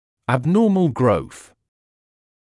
[æb’nɔːml grəuθ][эб’ноːмл гроус]аномальный рост